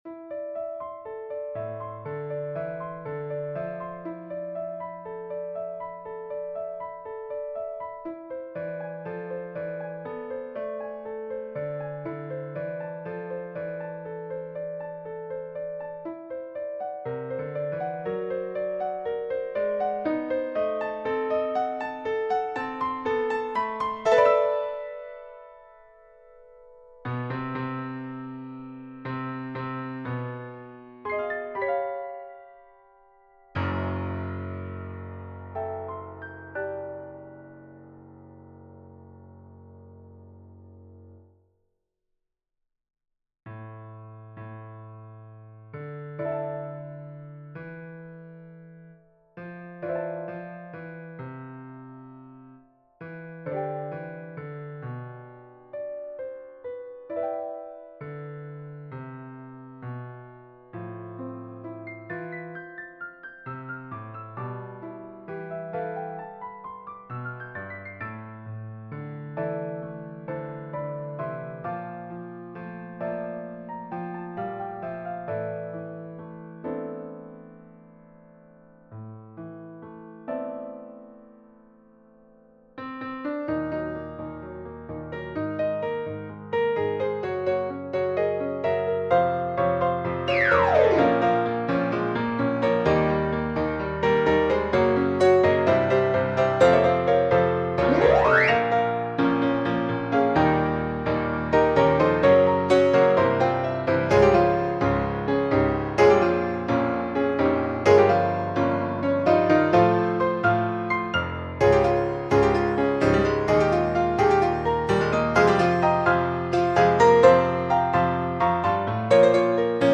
SAB mixed choir and piano
世俗音樂